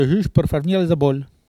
Il crie pour attirer l'essaim d'abeilles
Catégorie Locution